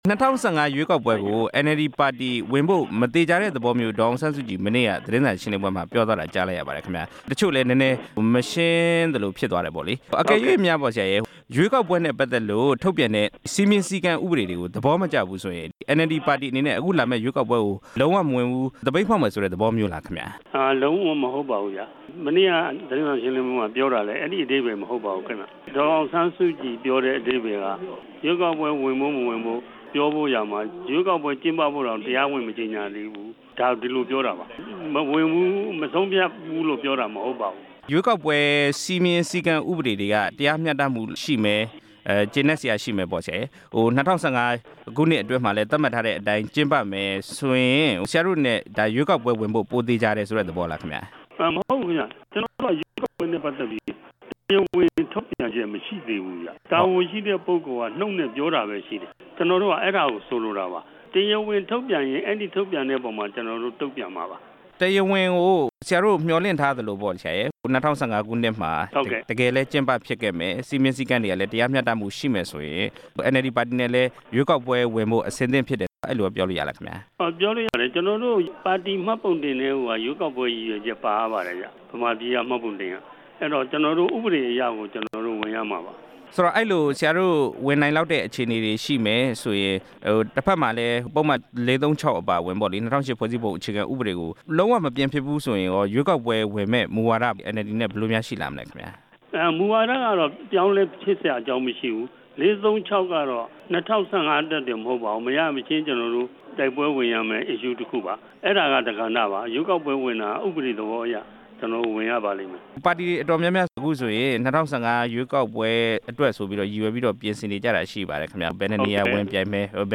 ၂ဝ၁၅ ရွေးကောက်ပွဲ ဝင်ရောက် ယှဉ်ပြိုင်ရေး NLD ပါတီရဲ့ မူဝါဒ သဘောထား မေးမြန်းချက်